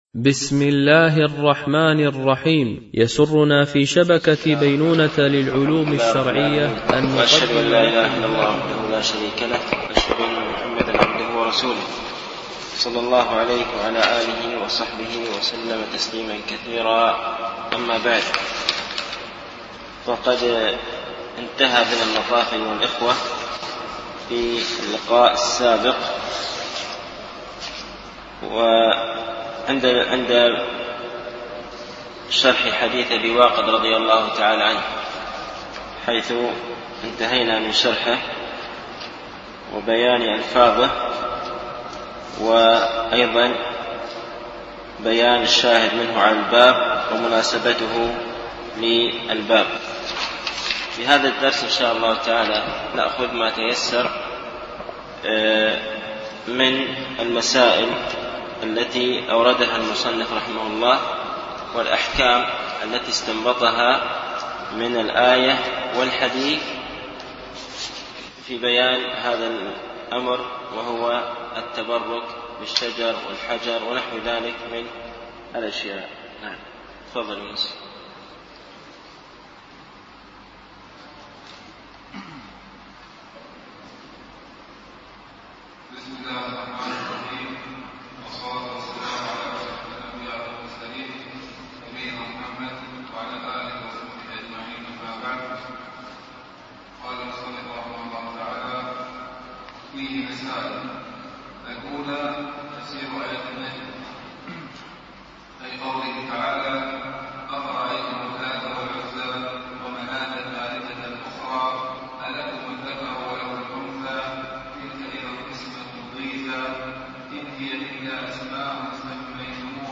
التعليق على القول المفيد على كتاب التوحيد ـ الدرس الرابع و العشرون